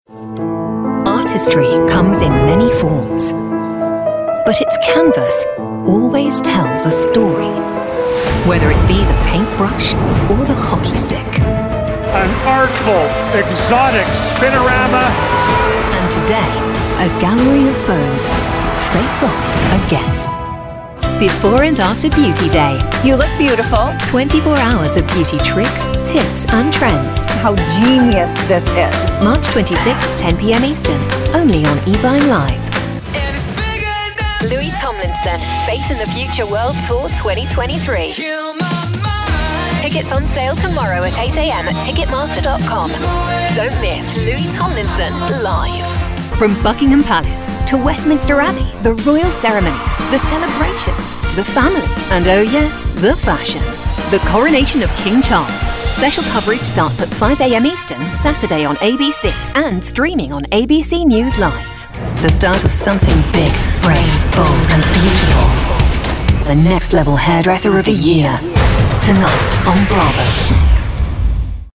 Promos
accented, accented English, announcer, bravado, british, high-energy, promo